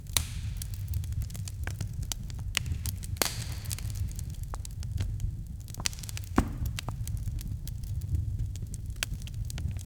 Sıcak Noel salonu ambiyansı: Sakin ve gerçekçi şömine odun çıtırtıları, hafif odun patlama sesleri, yumuşak oda yankısı.
scak-noel-salonu-ambiyans-yoohhoaw.wav